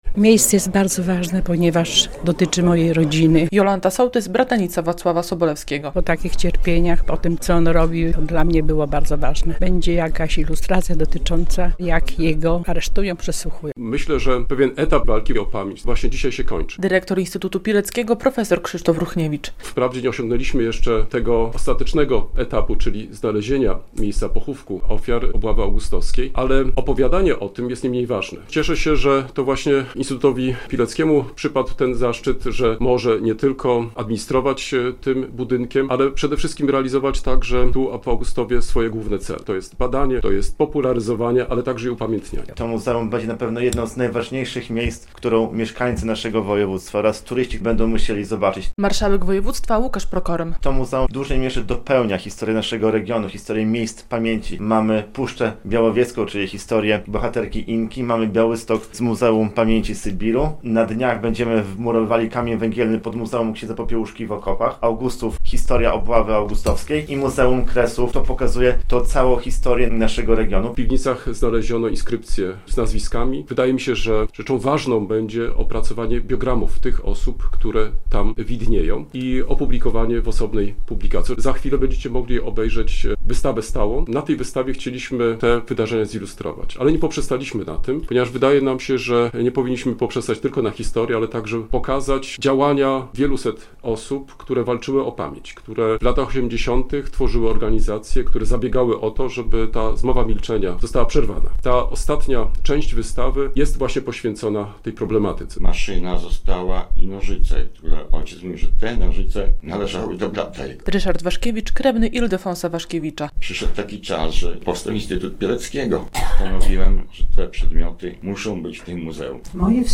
konferencja dotycząca obchodów 80. rocznicy Obławy Augustowskiej
Harmonogram obchodów przedstawiono w czwartek (10.07) podczas konferencji prasowej.